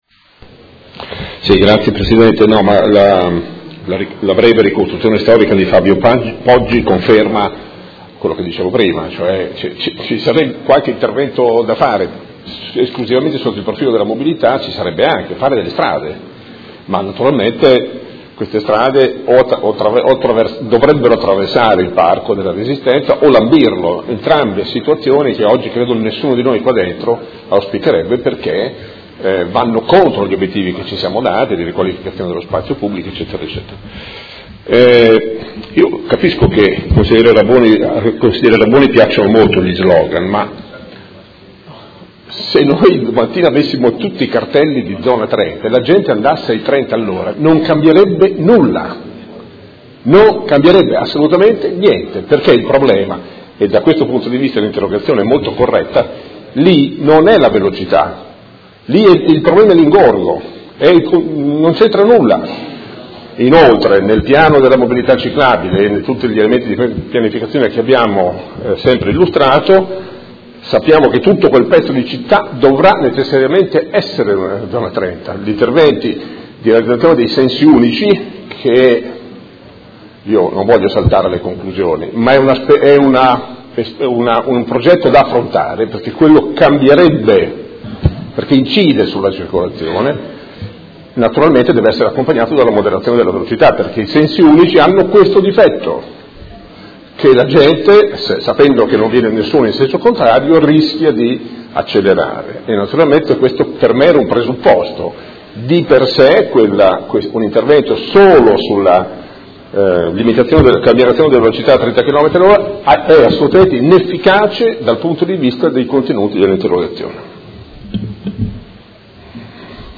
Seduta del 21/12/2017. Conclude dibattito su interrogazione del Consigliere Rocco (Art.1-MDP/Per Me Modena) avente per oggetto: Disagio viabilità Via La Spezia/Via Oneglia